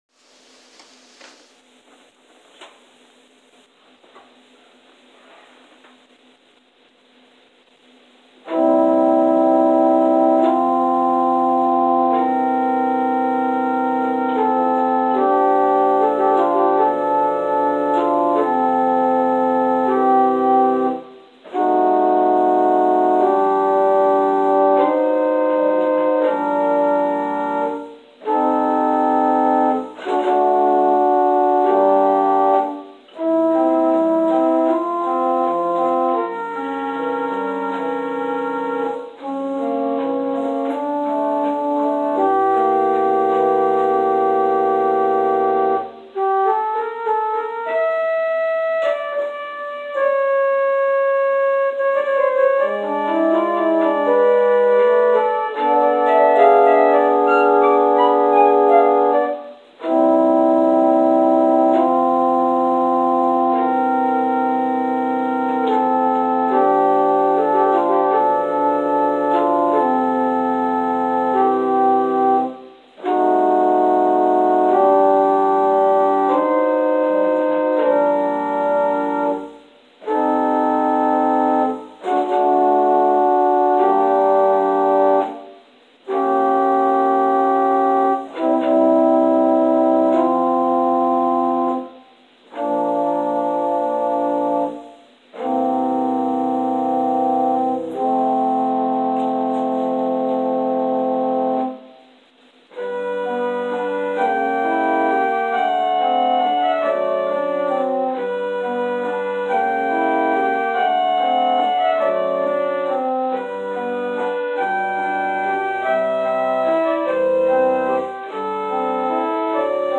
Prelude: “Scenes from Childhood” – Robert Schumann